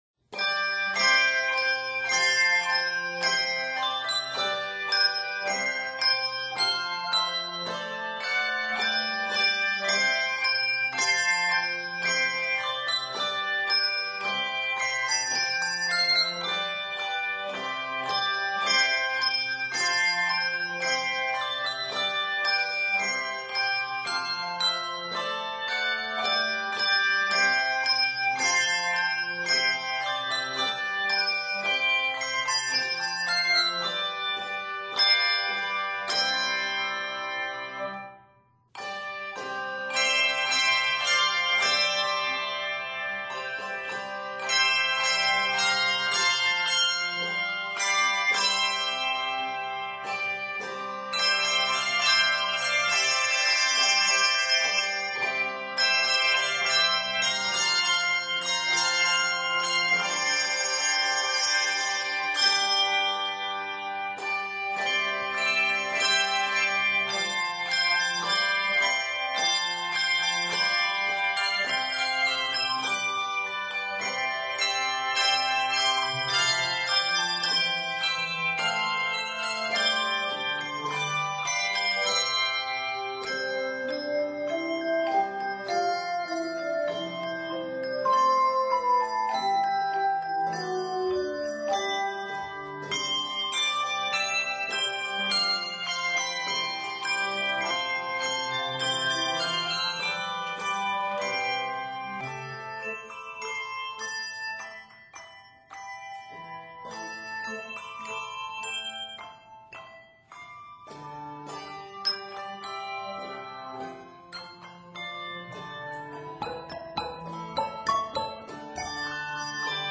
N/A Octaves: 3-5 Level